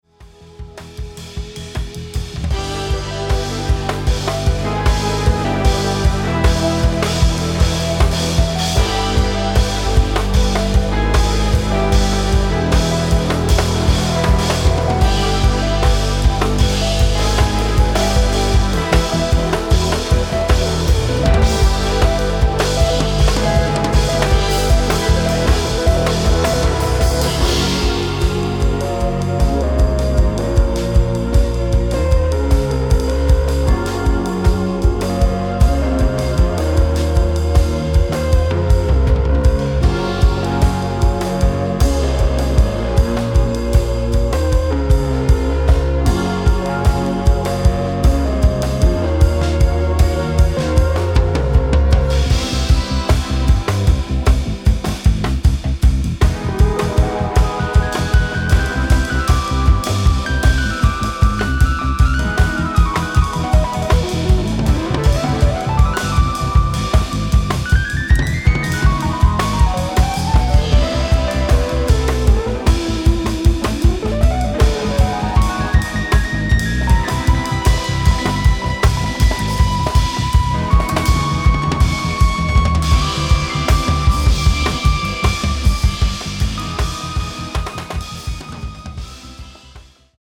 UK Jazz act
Broken beat, Experimental, Jazz